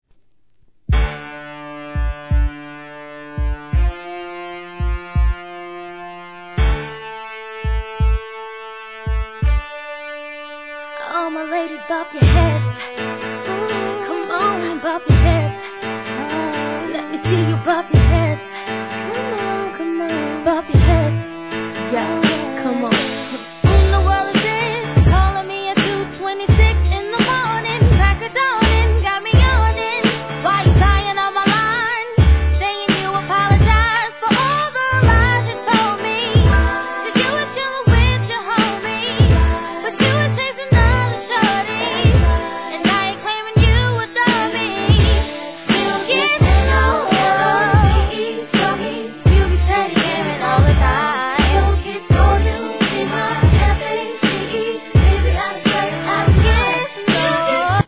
HIP HOP/R&B
激しく打ち込まれる重厚ビート上で息ぴったりのコーラス・ワークが見事!!